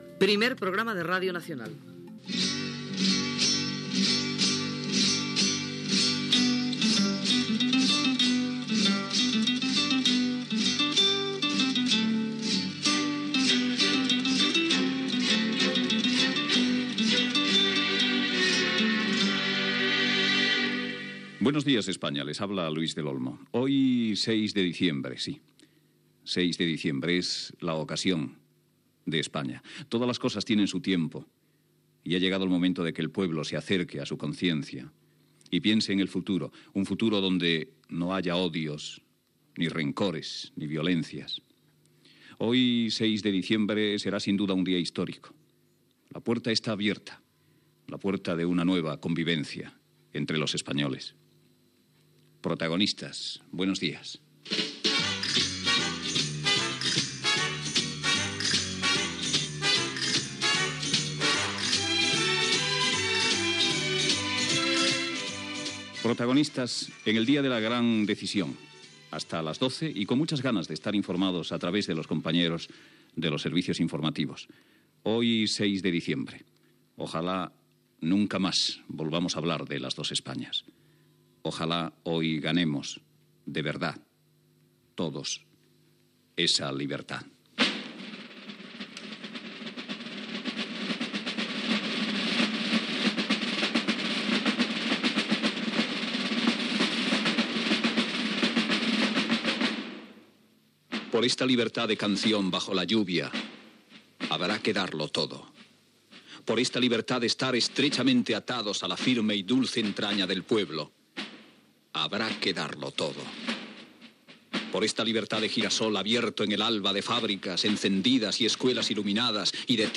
Identificació del "Primer programa de Radio Nacional", sintonia de l'emissora, inici del programa el dia que es vota la Constitució espanyola, sintonia del programa, poema sobre la llibertat
Info-entreteniment